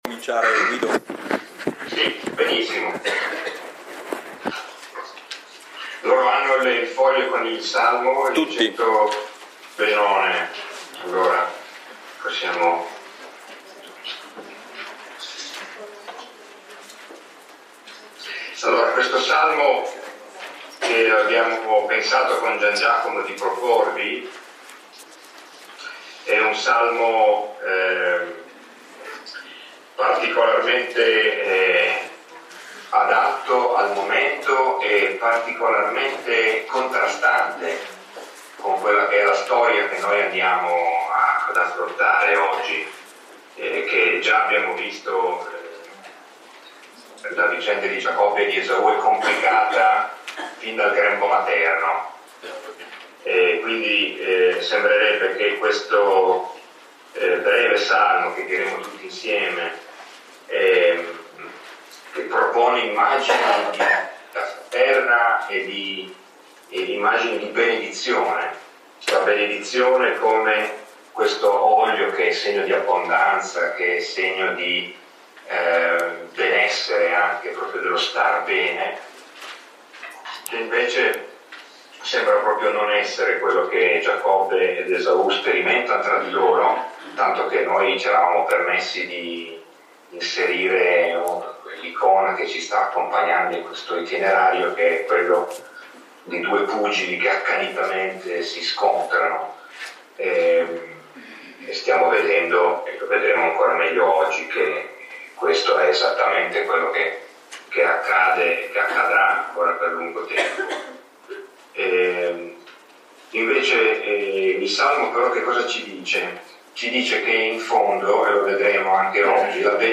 Lectio 3 – 21 dicembre 2014 – Antonianum – Padova